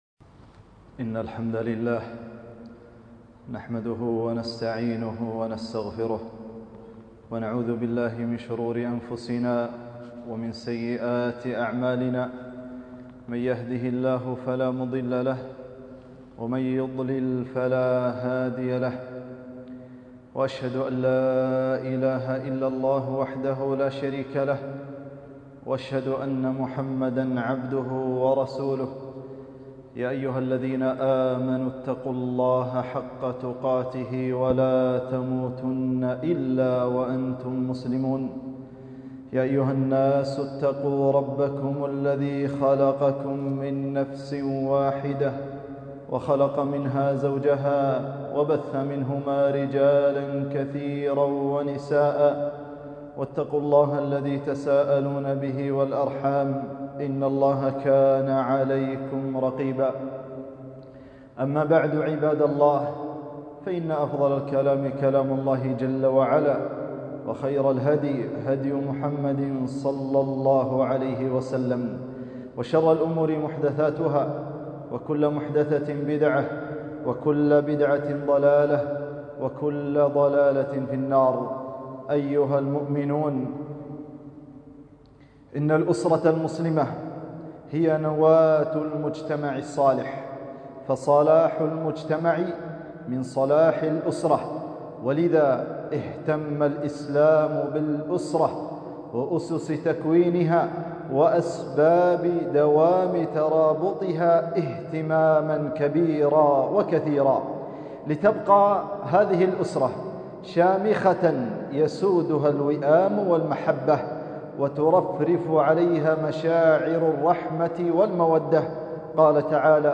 خطبة - مسؤولية الأسرة المسلمة